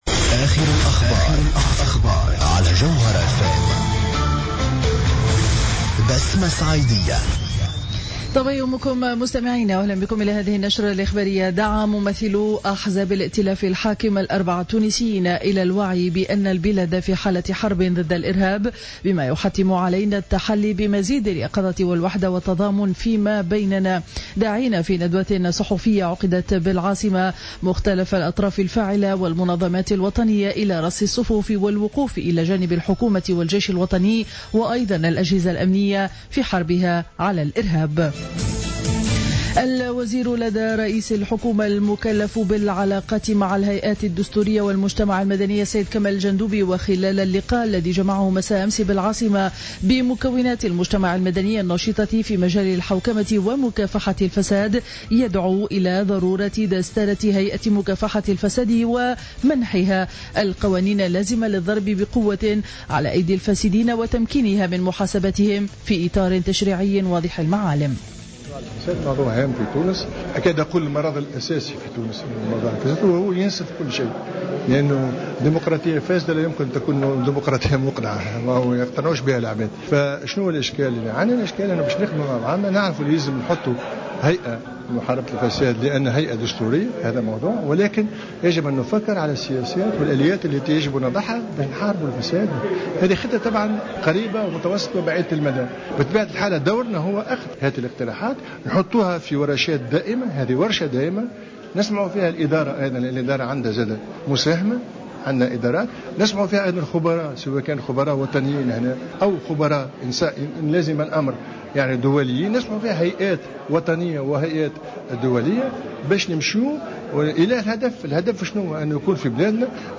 نشرة أخبار السابعة صباحا ليوم الثلاثاء 14 جويلية 2015